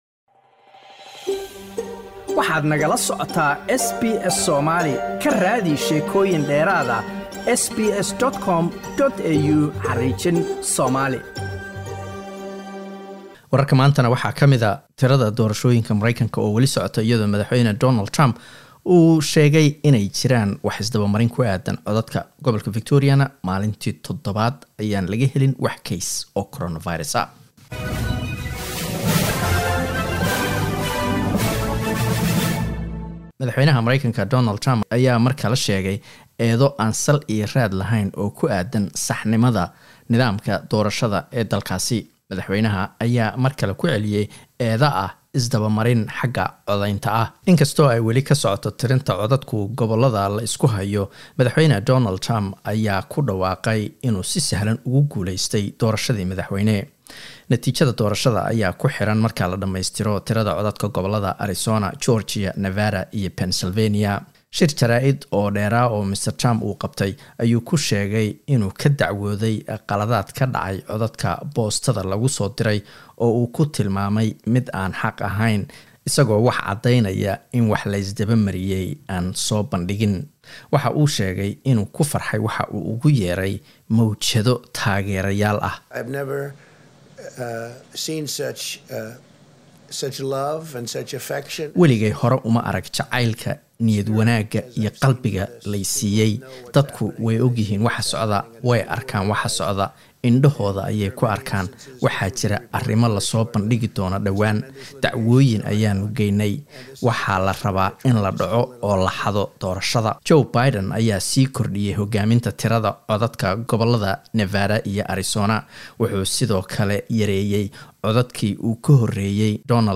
Wararka SBS Somali Jimco 6 Nofember